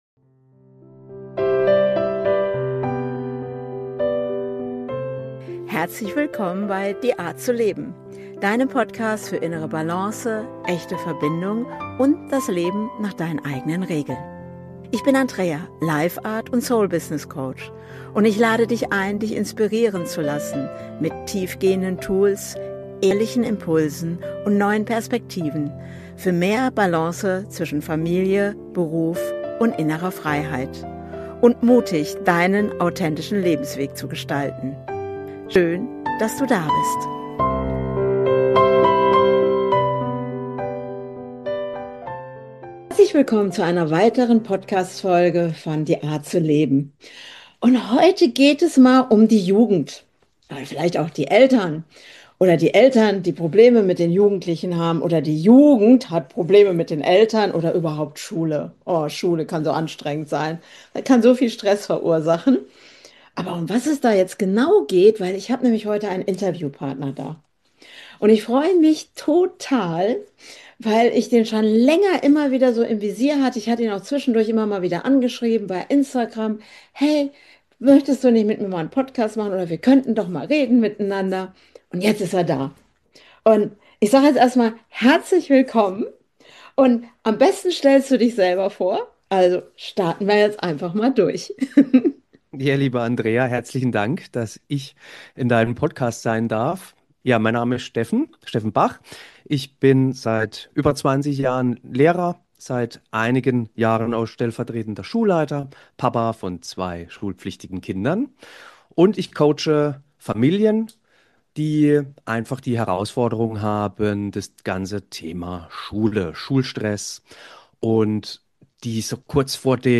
#122 Der Weg wurde immer klarer- Interview